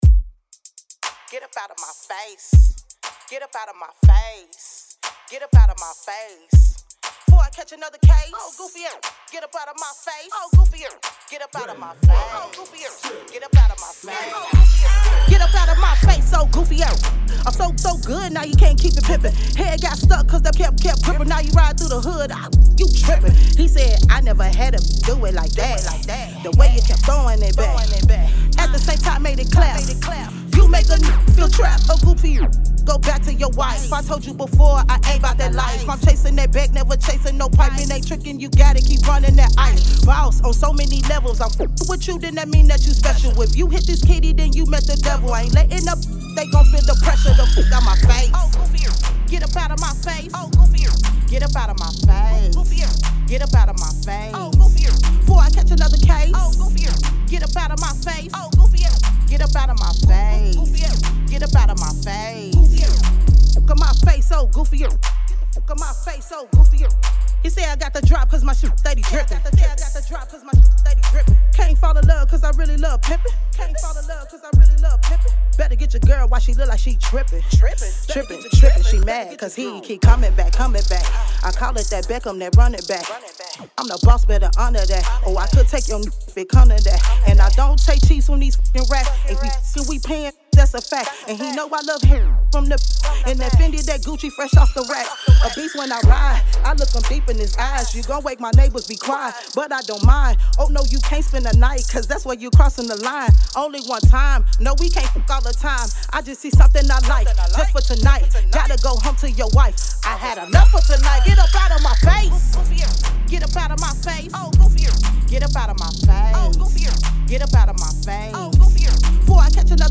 Hiphop
Description : CLEAN VERSION